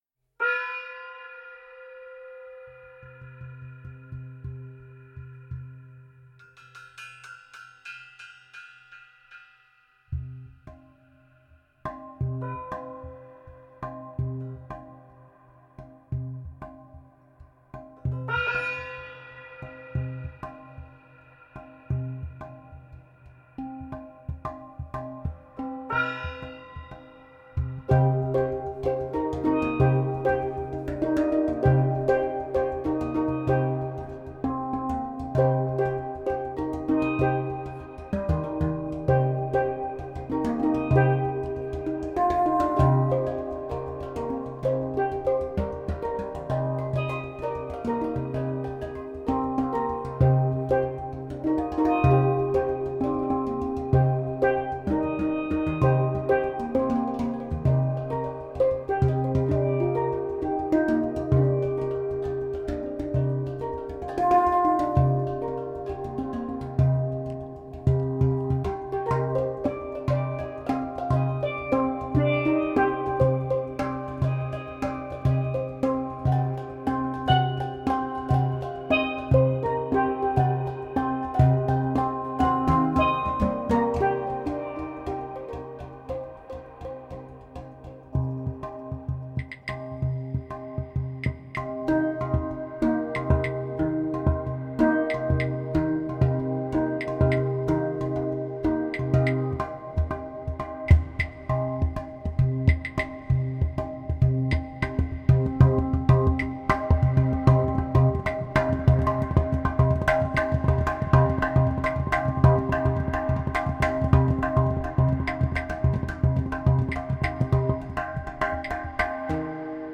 Space Drum et Steel Drum
Studio SLC
Impro-mix-1.mp3